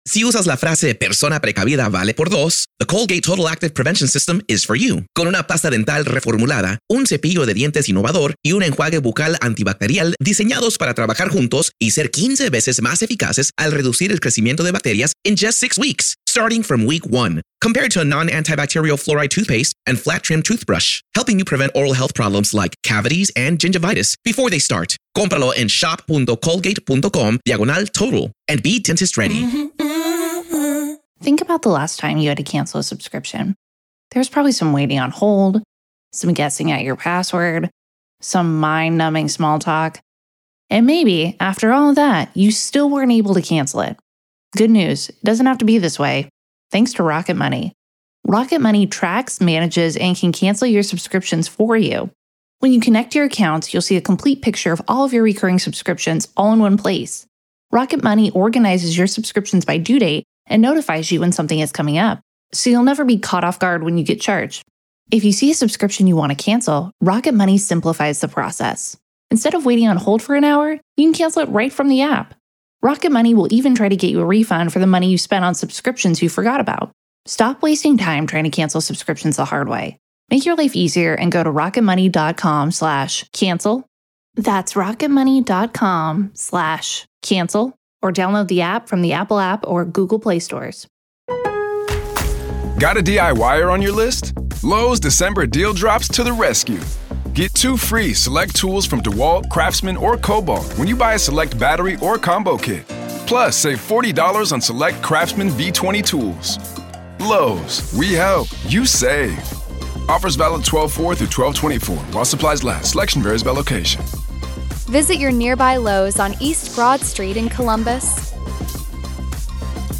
LIVE COURTROOM COVERAGE — NO COMMENTARY